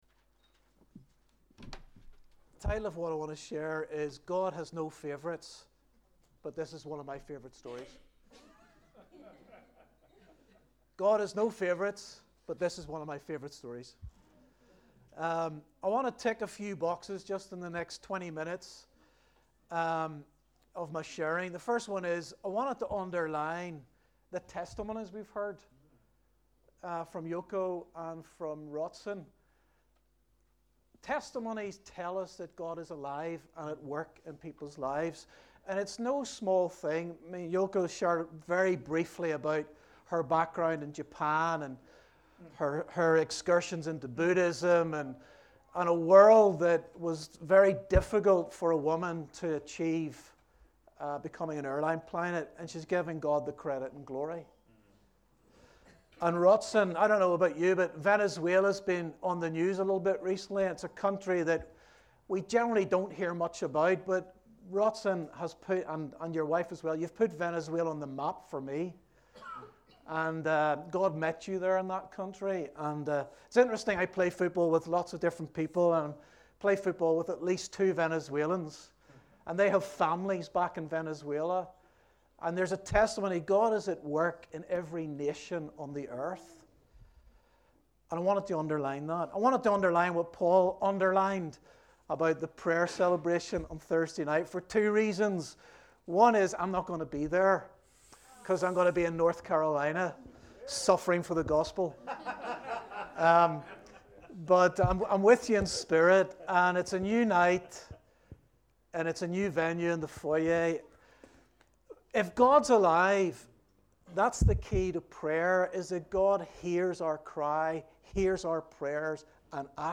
A message from the series "All Messages."